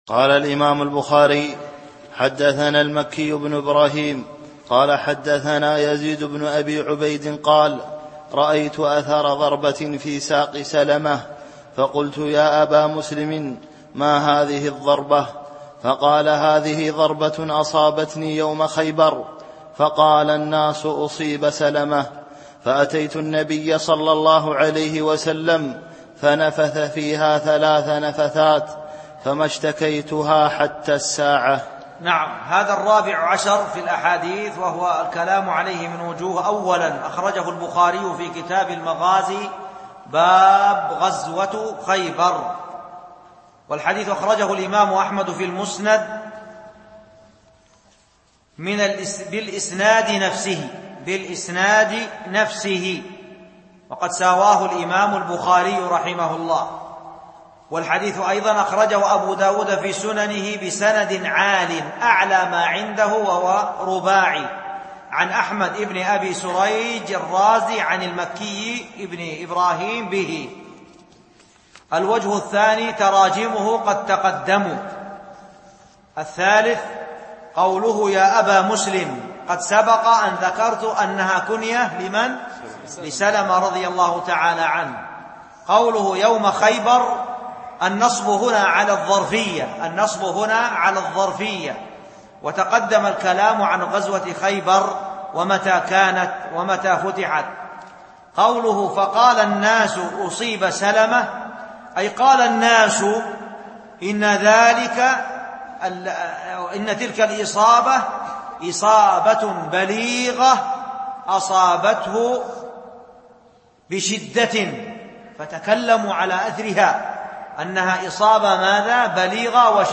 التعليق على الحديث (14) [4206] (رأيت أثر ضربة في ساق سلمة فقلت يا أبا مسلم ما هذه الضربة فقال هذه ضربة أصابتني يوم خيبر...)
MP3 Mono 22kHz 32Kbps (VBR)